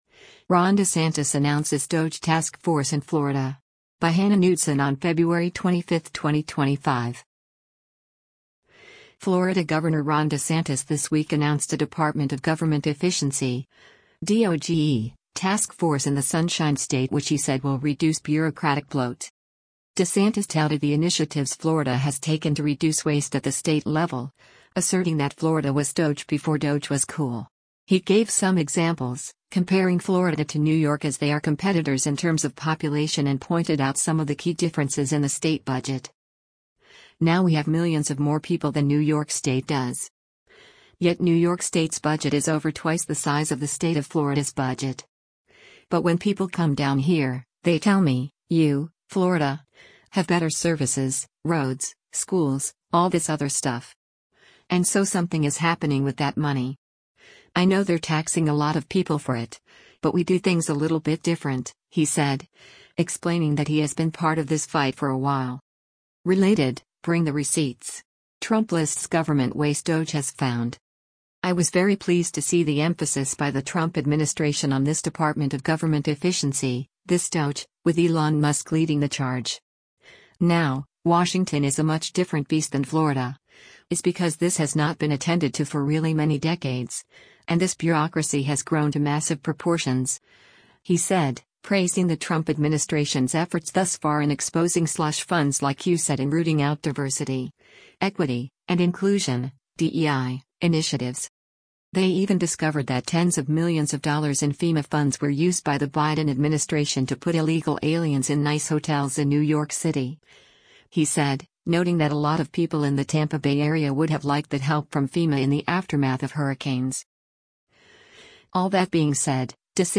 Florida Gov. Ron DeSantis gestures during a news conference, Wednesday, Sept. 25, 2024, at